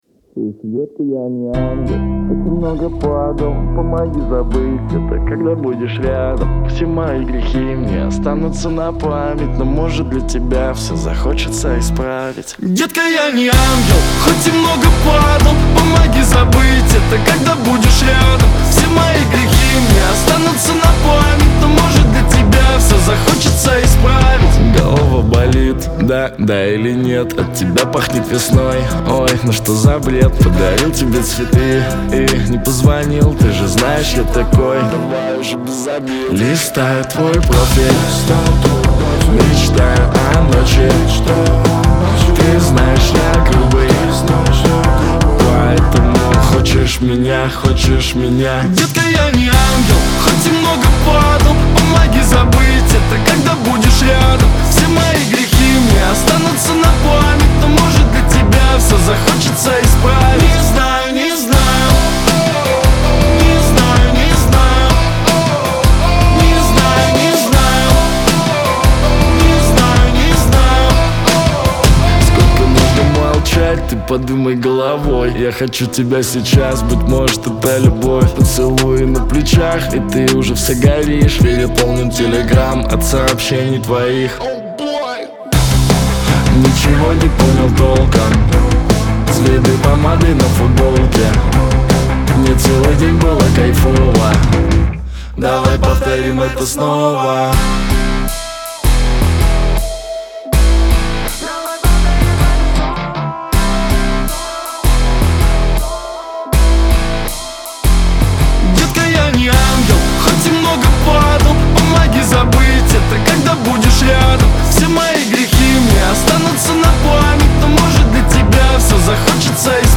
мощными гитарными рифами и запоминающимся мелодичным вокалом